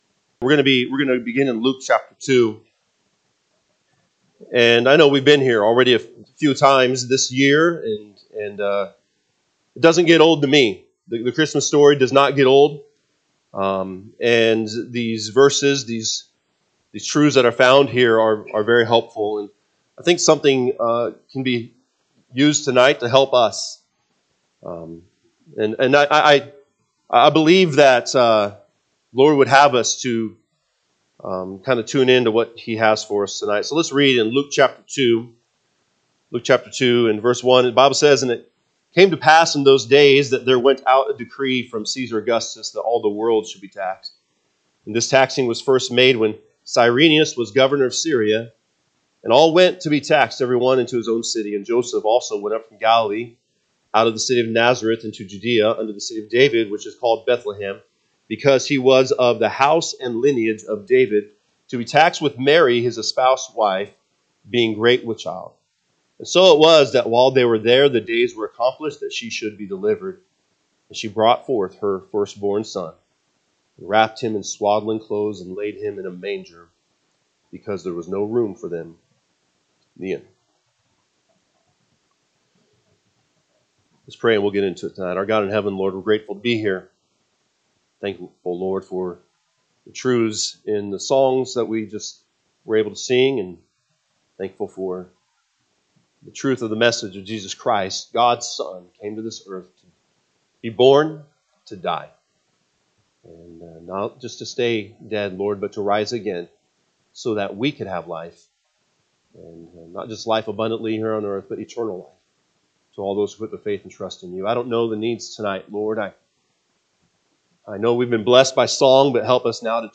Sunday PM Bible Study